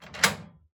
DoorUnlock.wav